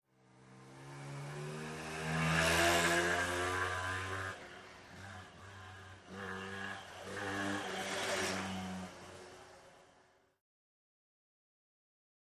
Motor Scooter, By Medium Speed, Cu.